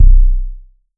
LIQUID BASS.wav